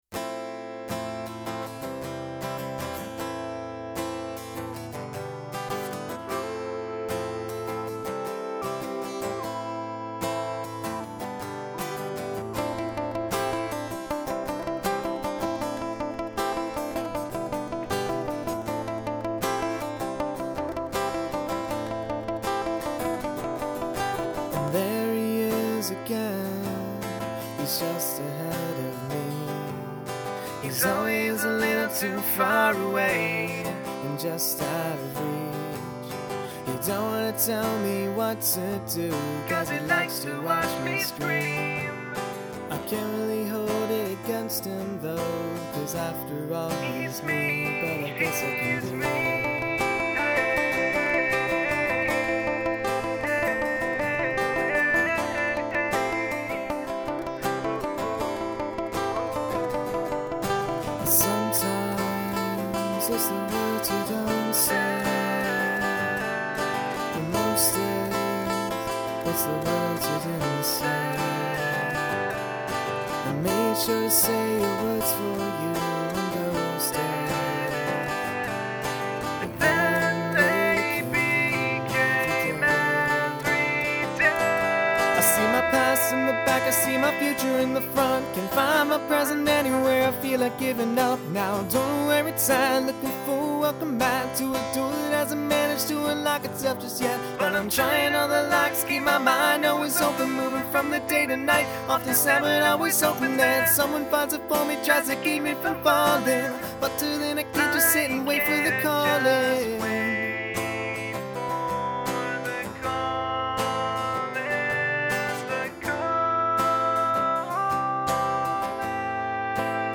Auto Tune